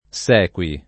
[ S$ k U i ]